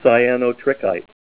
Say CYANOTRICHITE Help on Synonym: Synonym: Lettsomite